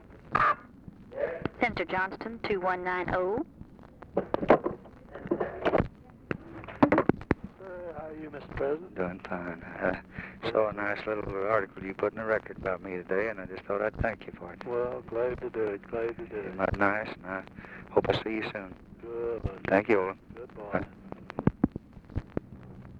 Conversation with OLIN JOHNSTON, December 5, 1963
Secret White House Tapes